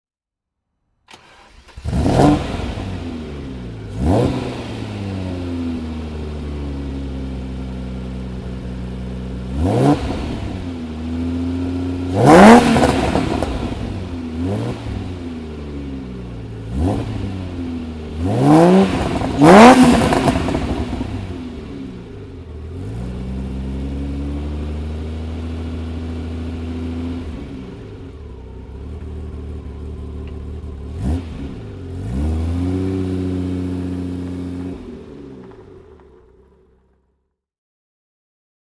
Ferrari Testarossa (1985) - Starten und Leerlauf
Ferrari_Testarossa_1985.mp3